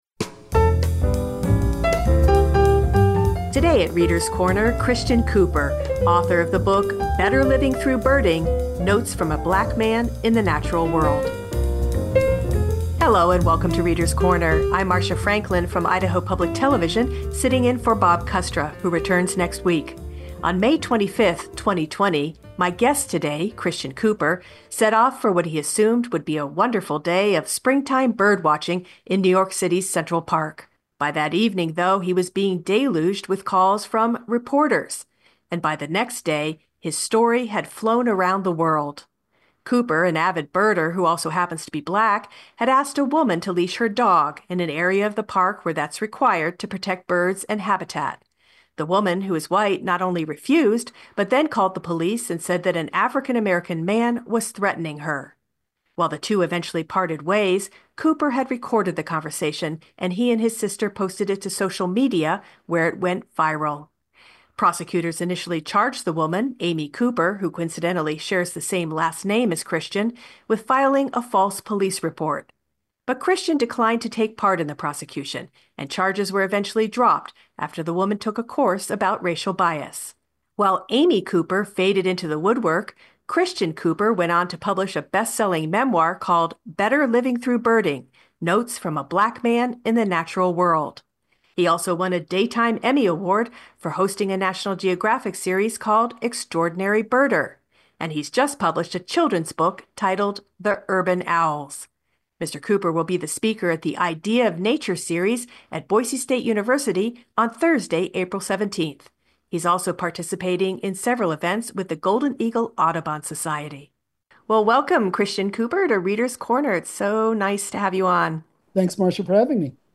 An interview with Christian Cooper, author of the book, Better Living Through Birding. Part memoir, part travelogue, the book explores how birding helped Cooper during the most difficult parts of his life, and how we can all benefit from looking up more.